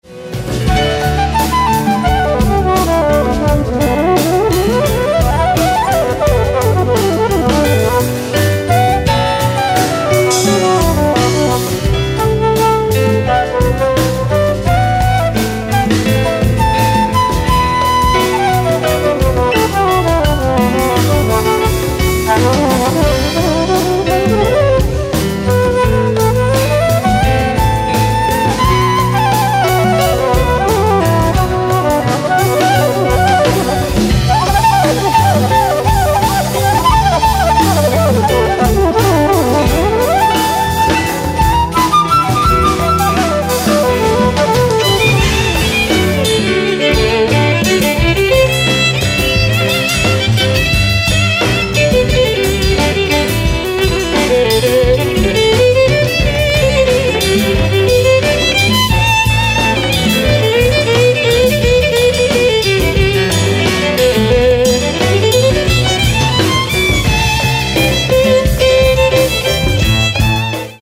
Live au FMPM 2006: